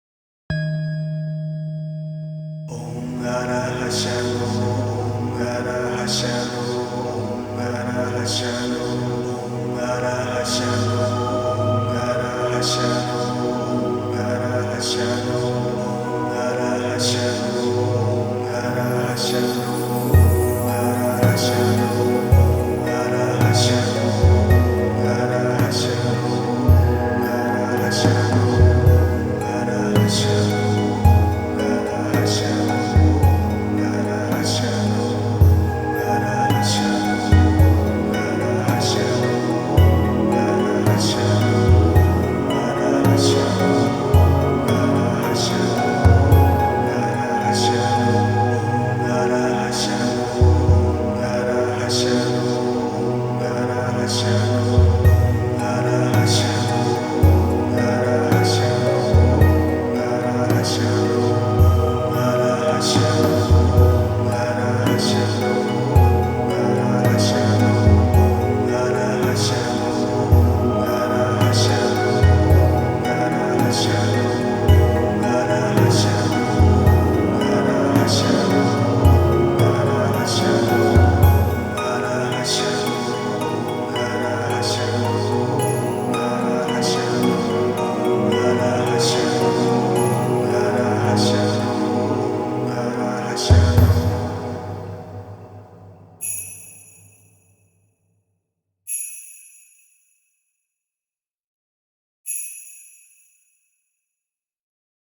智慧を司る仏様-文殊菩薩真言21回.mp3